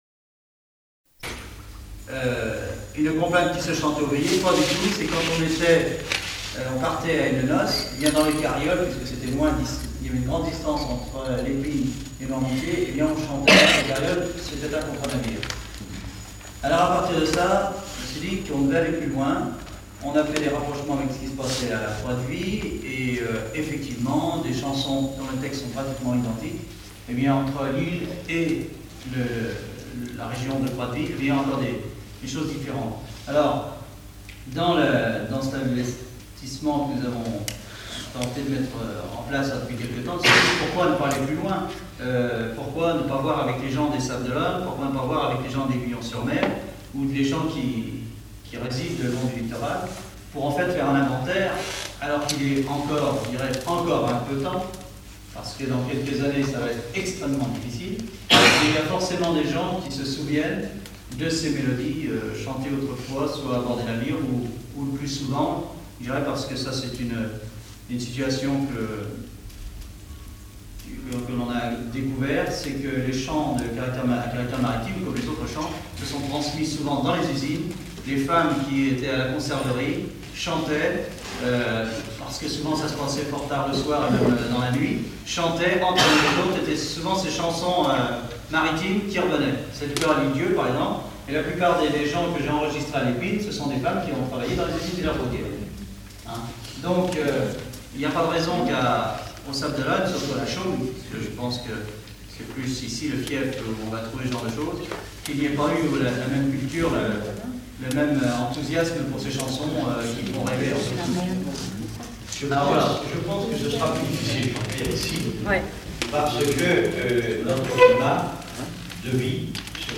Chansons et commentaires
Catégorie Témoignage